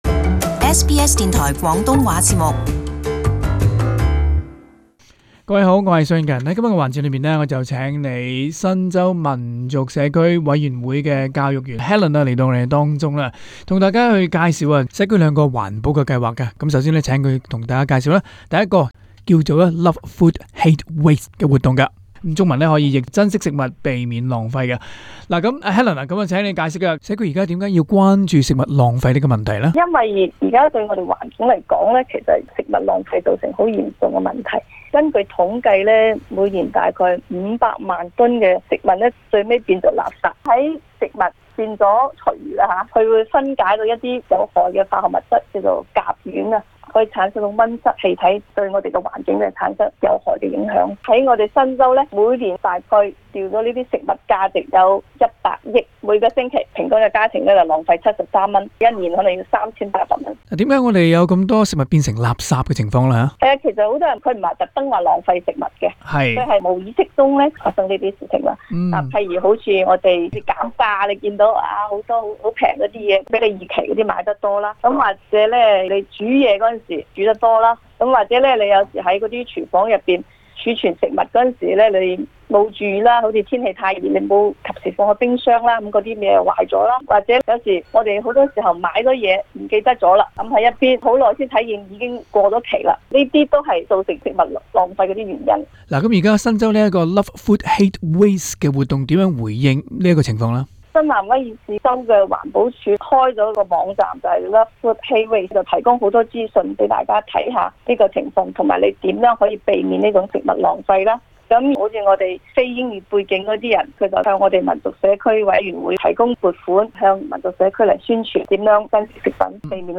READ MORE 【社區專訪】如何衡量嬰幼兒是否需要進行脊骨按摩？